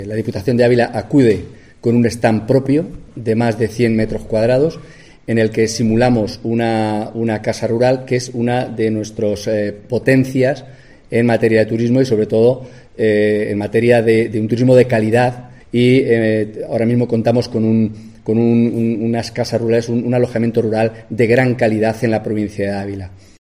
AUDIO / Armando García Cuenca, diputado de Turismo